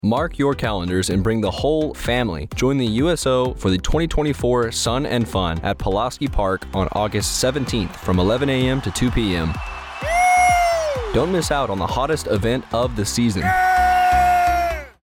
Radio Spot - Sun and Fun